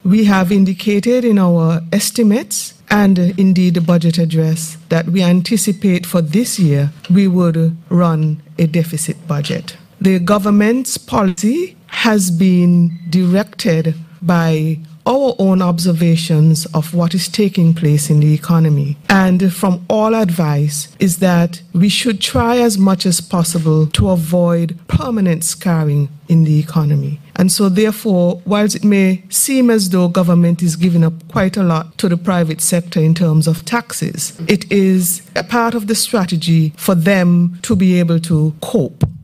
That was Financial Secretary, Mrs. Hilary Hazel.